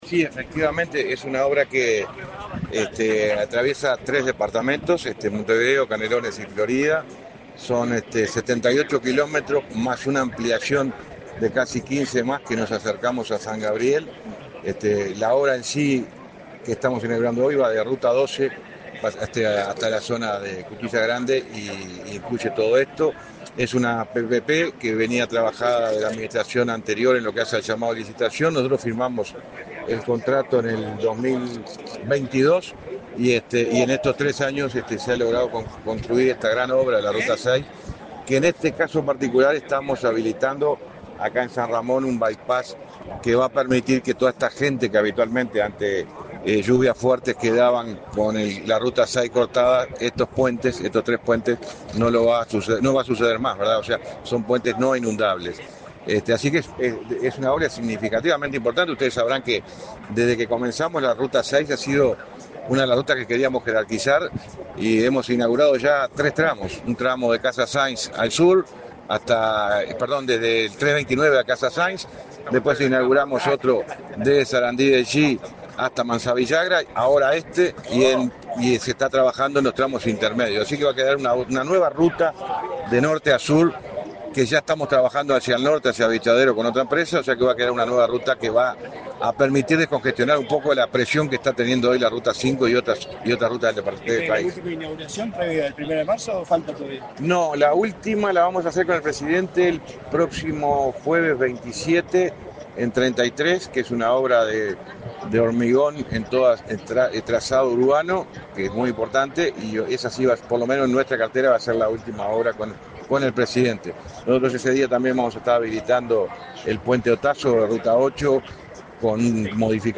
Declaraciones a la prensa del ministro de Transporte y Obras Públicas, José Luis Falero
Declaraciones a la prensa del ministro de Transporte y Obras Públicas, José Luis Falero 19/02/2025 Compartir Facebook X Copiar enlace WhatsApp LinkedIn El presidente de la República, Luis Lacalle Pou, participó, este 19 de febrero, en la inauguración de obras de rehabilitación de 78 kilómetros de la ruta n.° 6, en Canelones. En la oportunidad, el ministro de Transporte y Obras Públicas, José Luis Falero, realizó declaraciones a la prensa.